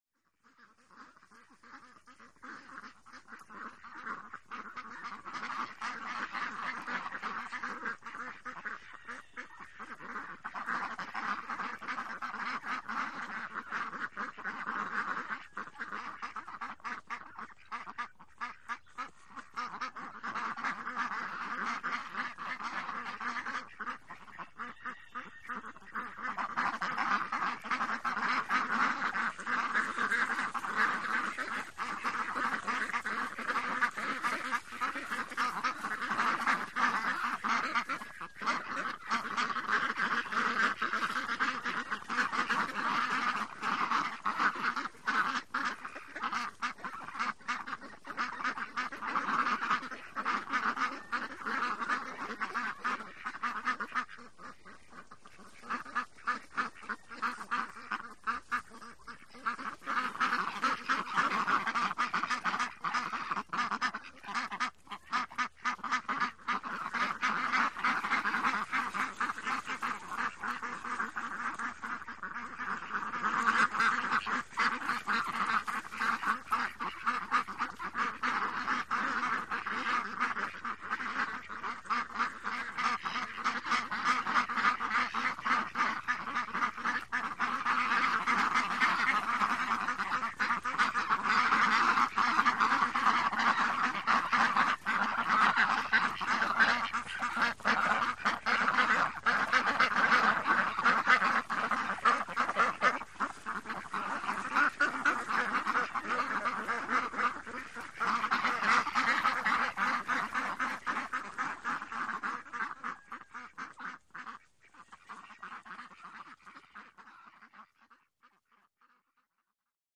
Several Ducks Quack, Move Through Grass, Distant Rooster Calls.